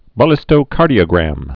(bə-lĭstō-kärdē-ə-grăm)